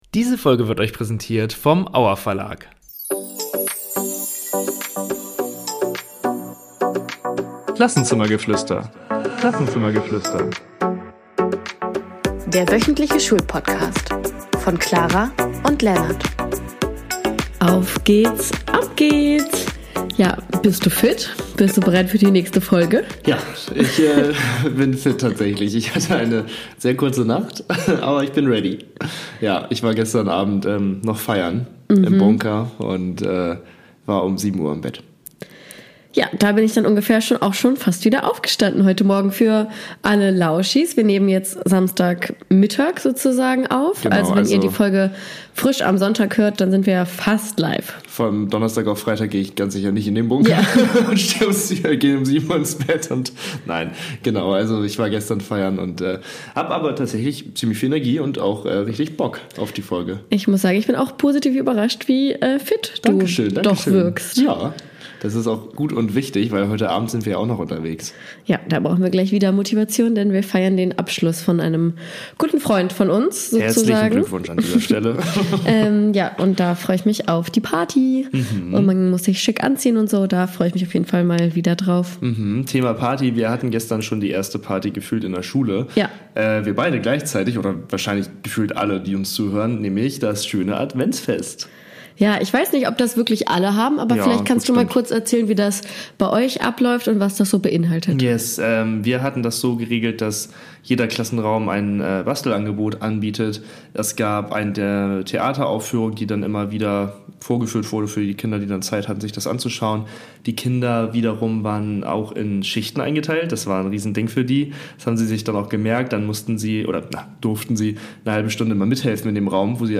Natürlich versteckt sich auch in dieser Folge wieder ein weihnachtliches Geräusch, es bleibt also adventlich oder so ähnlich...